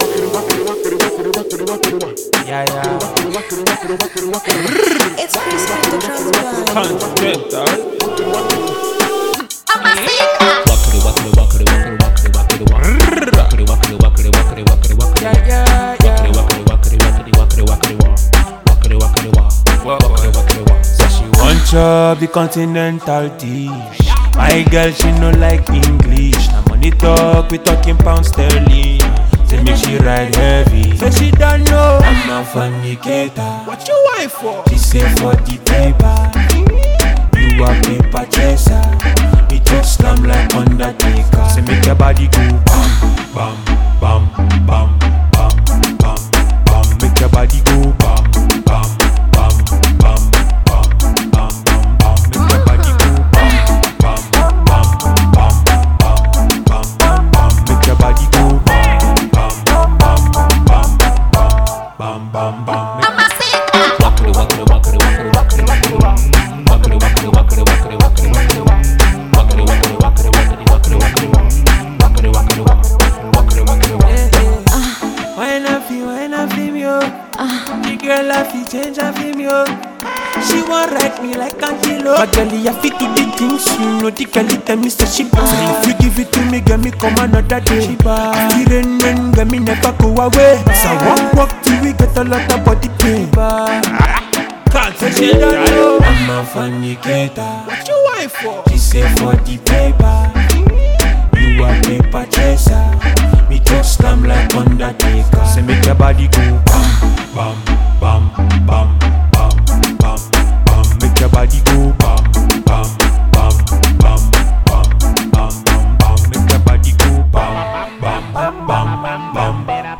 dancehall vocal prowess